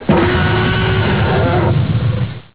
Jurassic Park: Tyrannosaurus rex 4
jp-trex4.wav